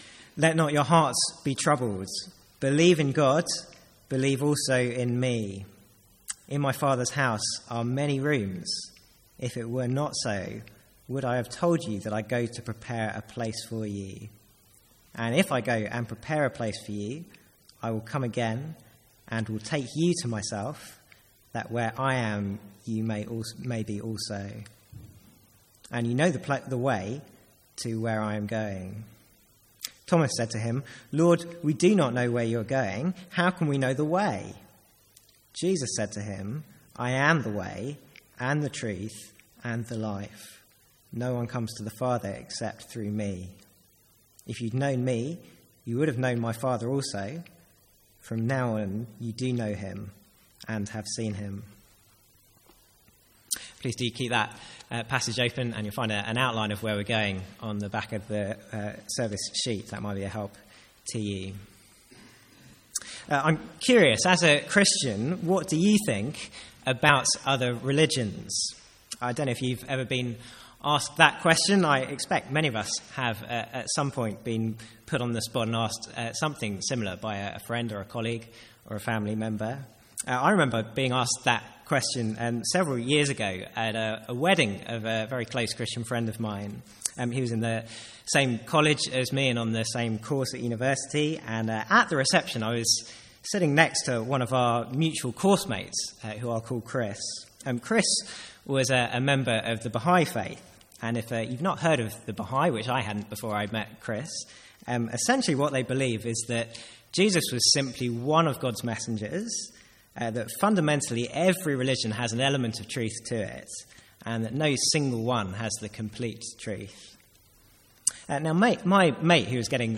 Sermons | St Andrews Free Church
From the Sunday evening series 'Hard Sayings of Jesus'.